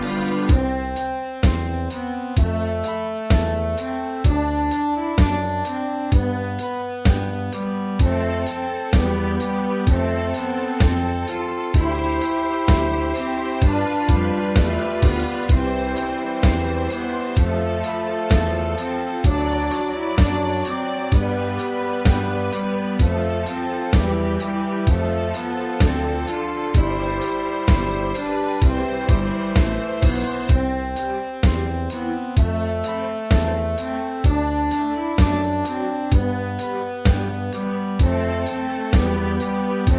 大正琴の「楽譜、練習用の音」データのセットをダウンロードで『すぐに』お届け！
Unison musical score and practice for data.